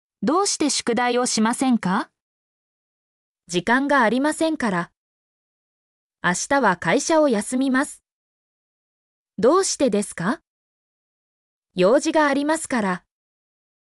mp3-output-ttsfreedotcom-36_fgwNqADH.mp3